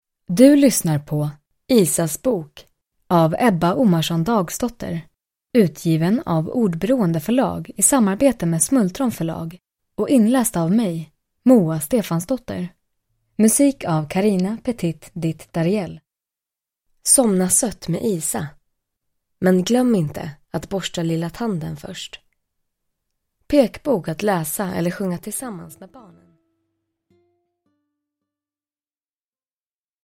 Isas bok – Ljudbok – Laddas ner